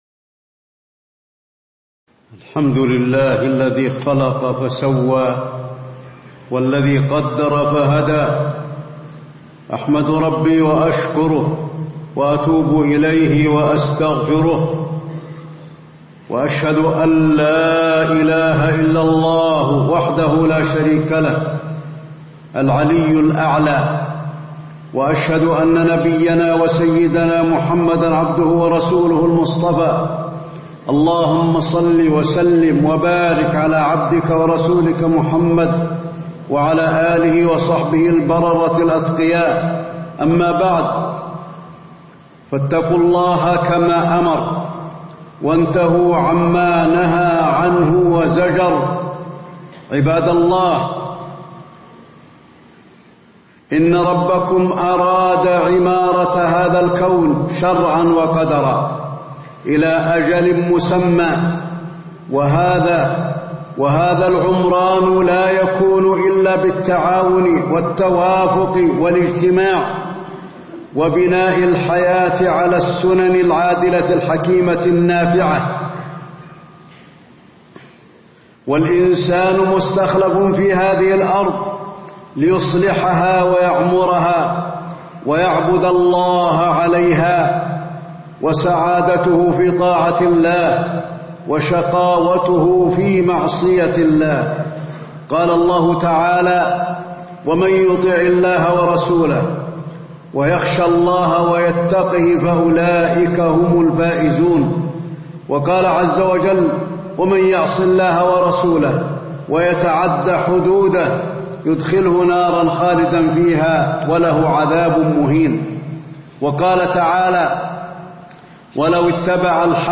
تاريخ النشر ٢٩ صفر ١٤٣٧ هـ المكان: المسجد النبوي الشيخ: فضيلة الشيخ د. علي بن عبدالرحمن الحذيفي فضيلة الشيخ د. علي بن عبدالرحمن الحذيفي فوائد الزواج وأحكامه